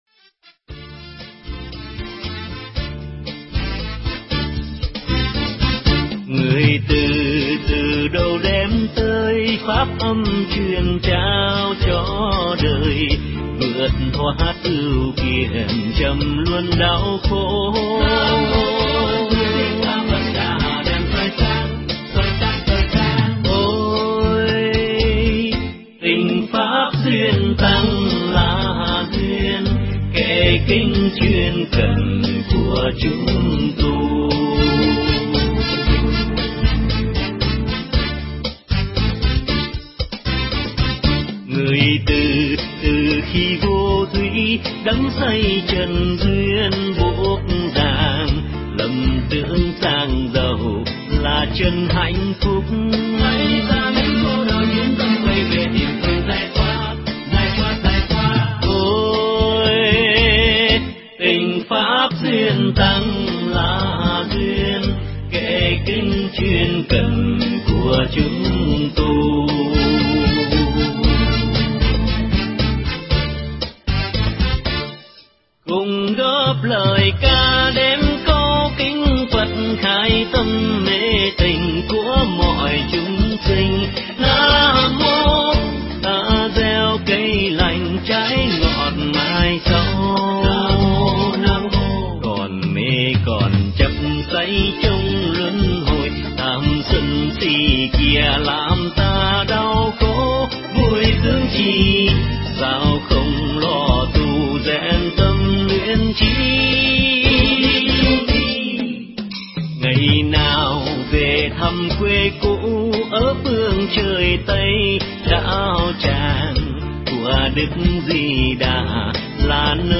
Mp3 Pháp Thoại Nhịp cầu mùa hạ được
thuyết giảng tại chùa Từ Thuyền, Quận Bình Thạnh